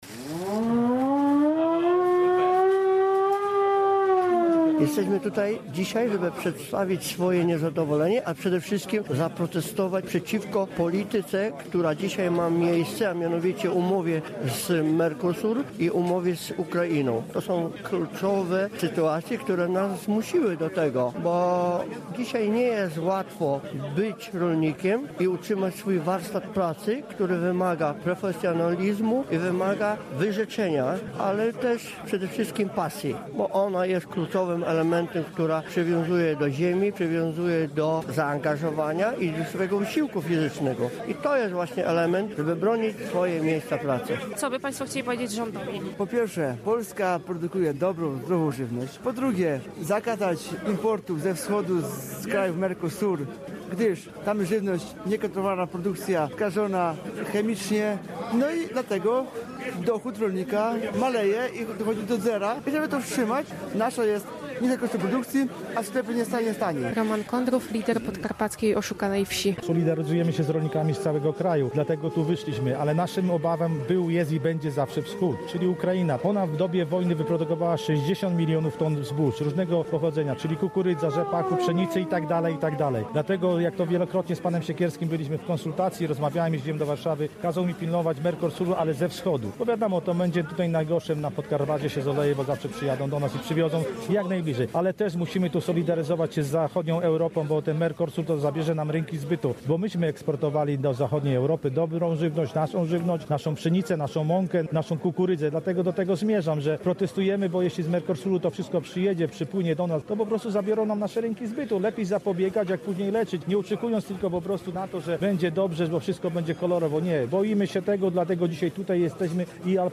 Protest rolników w Rzeszowie przeciw umowie UE–Mercosur i liberalizacji handlu z Ukrainą • Relacje reporterskie • Polskie Radio Rzeszów
Manifestacja odbyła się dziś przed siedzibą Podkarpackiego Urzędu Wojewódzkiego w Rzeszowie.